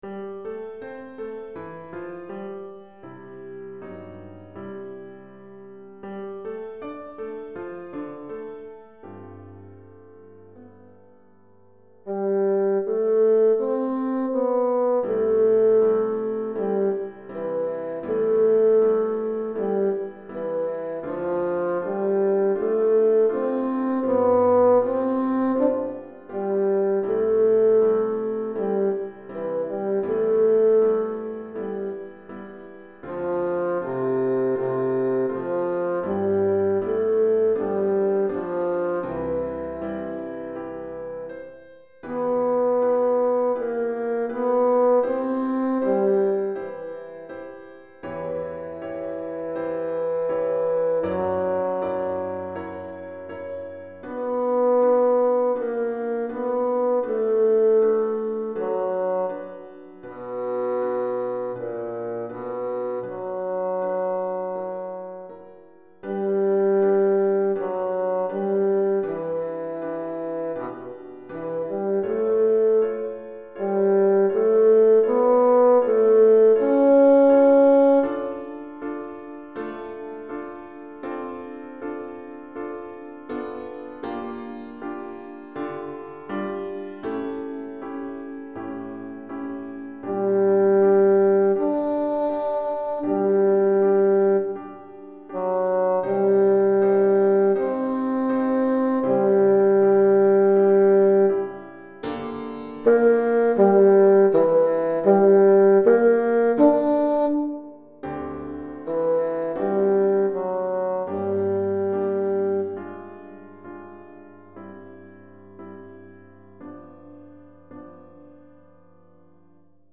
Pour basson et piano
Basson et piano